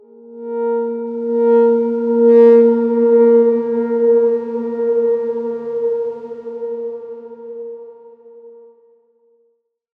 X_Darkswarm-A#3-mf.wav